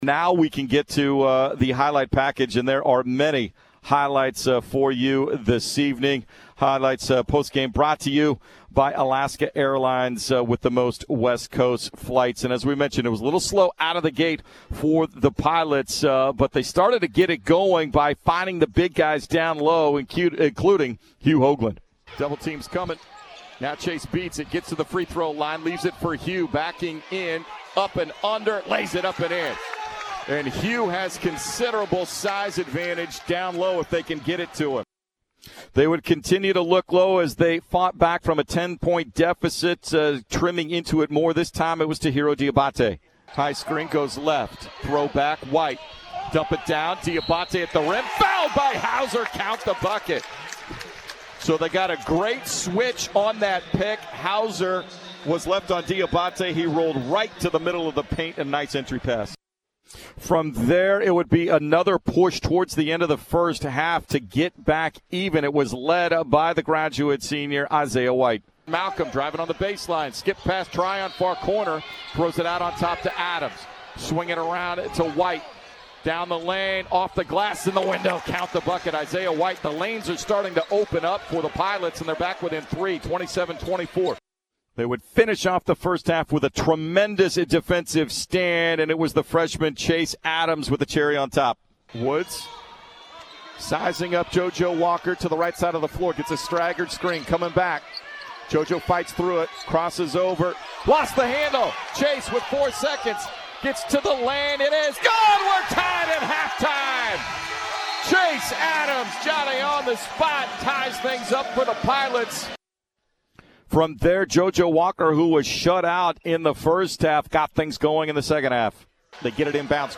Men's Hoops Post-Game Highlights at Portland State